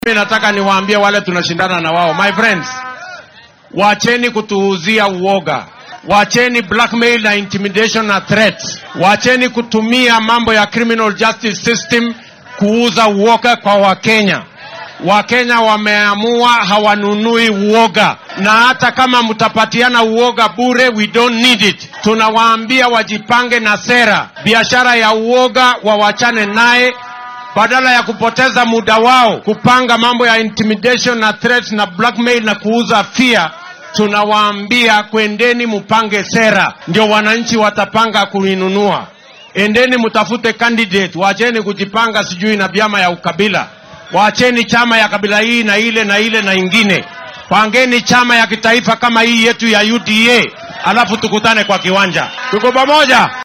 Madaxweyne ku xigeenka dalka William Ruto ayaa kenyaanka ku boorriyay in sanadka soo socdo ay doortaan hoggaamiyeyaal aragti fog leh balse aynan marnaba oggolaan in loo far fiiqo mas’uuliyiinta matalaya. Waxaa uu hoosta ka xarriiqay inay lagama maarmaan tahay in musharraxa fursad lagu siiyo sida ay uga go’an tahay mideynta bulshada. Xilli uu shalay ku sugnaa Koonfurta ismaamulka Kajiado ayuu hoggaamiyaha labaad ee wadanka xusay in Kenya ay ku tallaabsatay horumar wax ku ool ah sidaas awgeedna aan dib loogu noqon karin siyaasadda kala qaybinta ku saleysan.